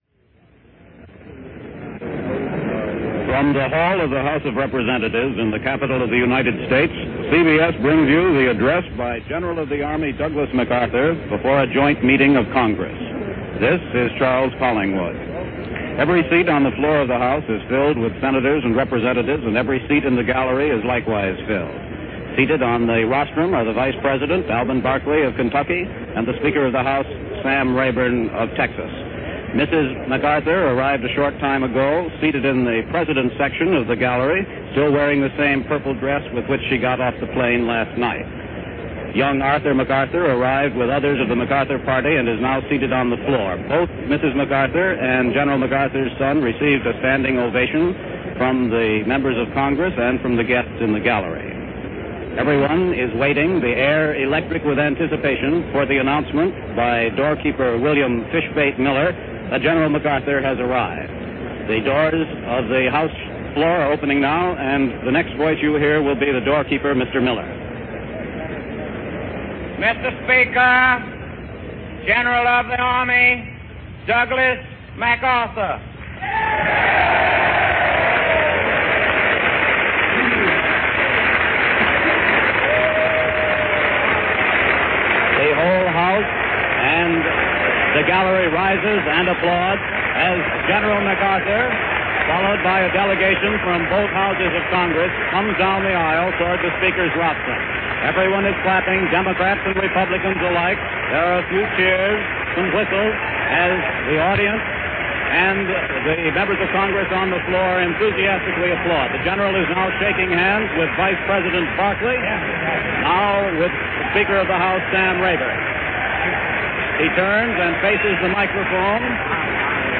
April 19, 1951 - General Douglas MacArthur Farewell To Congress - Gen. MacArthur addresses a joint session of Congress after his dismissal.
He addressed a joint session of Congress on April 19, 1951: